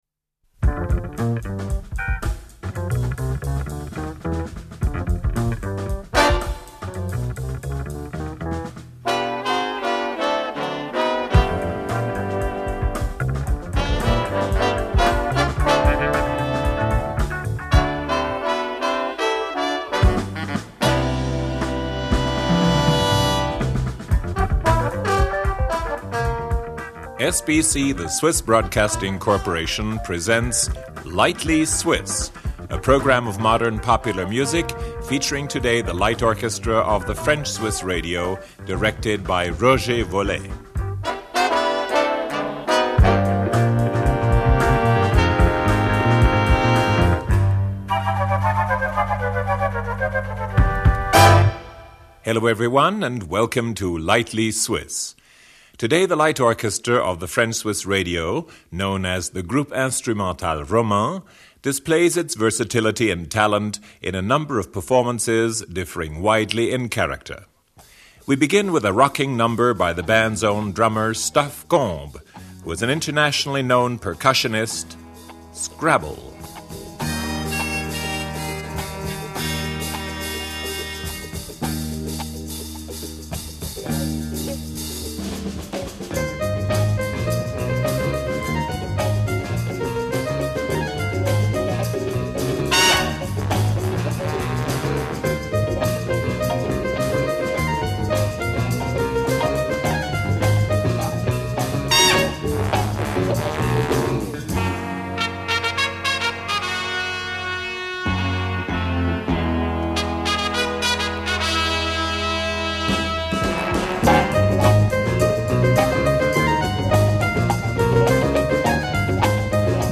trumpet.
vocal effects.